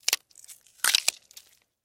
Звук прокусывания толстой кожи человека пиявкой